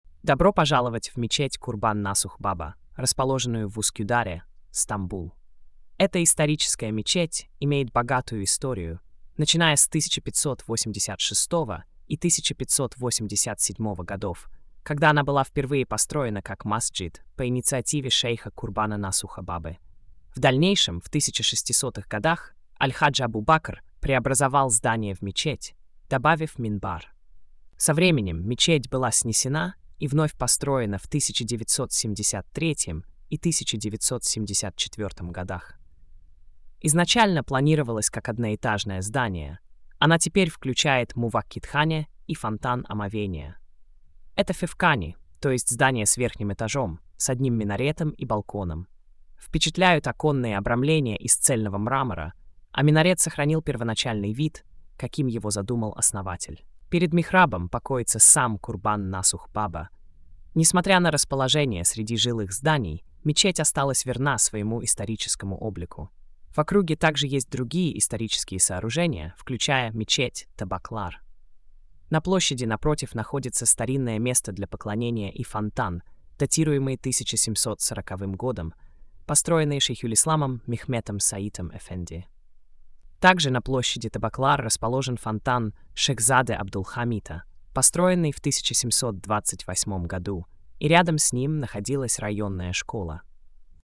Аудиоповествование